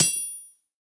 hit_golden_crate_01.ogg